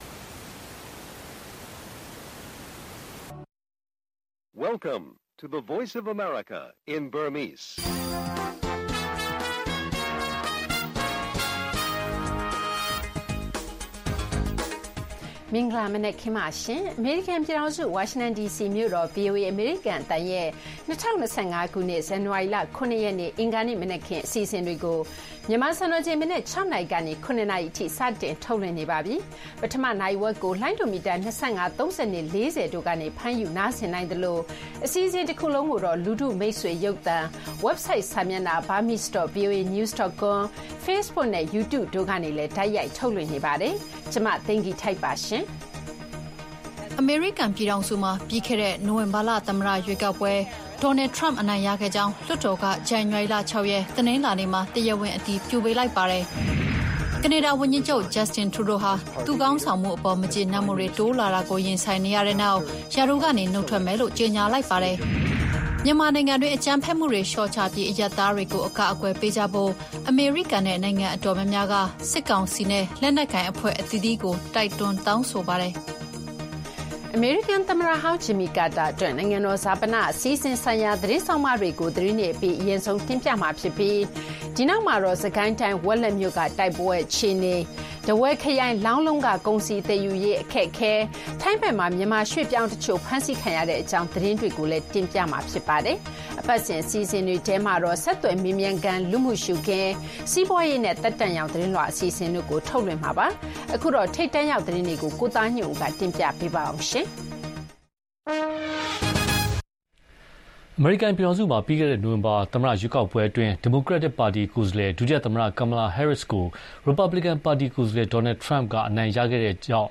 ဗွီအိုအေမြန်မာနံနက်ခင်း(ဇန်နဝါရီ ၇၊ ၂၀၂၅) ကန်ရွေးကောက်ပွဲ ထရမ့်အနိုင်ရကြောင်း လွှတ်တော်အတည်ပြု၊ ကနေဒါဝန်ကြီးချုပ် ရာထူးကနုတ်ထွက်မည်ဟုကြေညာ၊ မြန်မာနိုင်ငံတွင်းအရပ်သားတွေကာကွယ်ပေးကြဖို့ ကန်နဲ့နိုင်ငံများတိုက်တွန်း စတဲ့သတင်းတွေနဲ့ အပတ်စဉ်အစီအစဉ်တွေ ထုတ်လွှင့်ပေးပါမယ်။